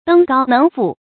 登高能賦 注音： ㄉㄥ ㄍㄠ ㄣㄥˊ ㄈㄨˋ 讀音讀法： 意思解釋： 賦：寫作。